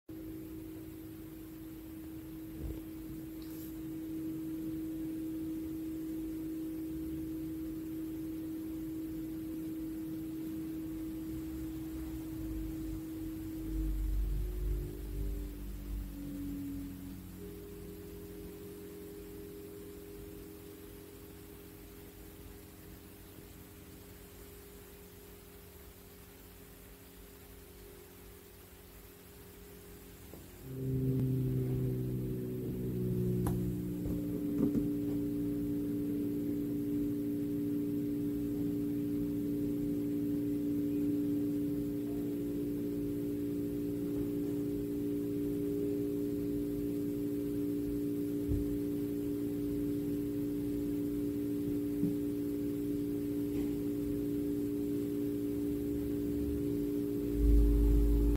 Mais c'est la que le problème commence, même éteint, on entend le bruit de l'unité extérieur dans le split intérieur !
Un vrai bruit d'avion. cry
J'ai fait une petite video, le bruit n'est pas très fort mais la nuit ca empêche clairement de dormir...
On entend un changement important à la 31ème seconde ; est-ce parce que vous avez assemblé 2 enregistrements, ou est-ce une prise de son d'un seul tenant?
bruit-split-mitsubishi-eteint.mp3